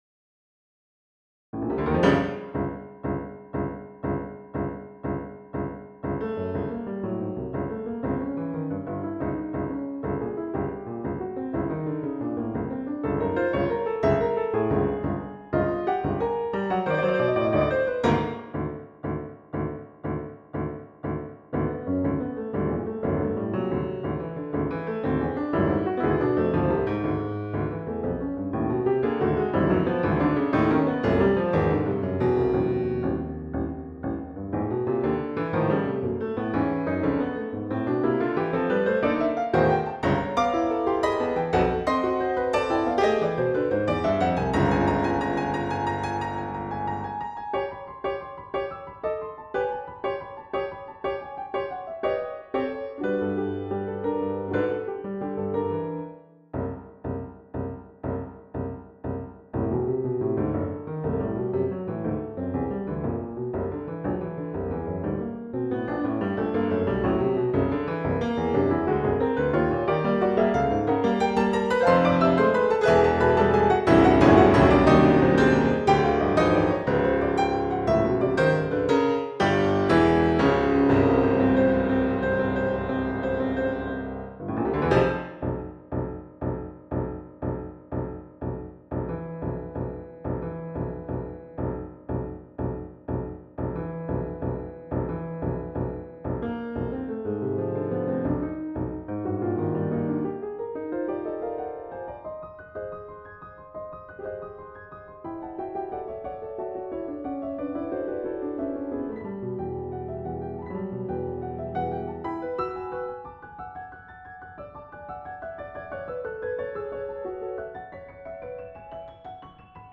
Sketch No. 104 - Piano Music, Solo Keyboard - Young Composers Music Forum
This sketch was originally supposed to be a piano sonata, but I ran out of ideas for it after an hour of writing so I ended it on the C# minor chord and called it a day.